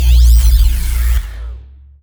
sci-fi_electric_pulse_hum_03.wav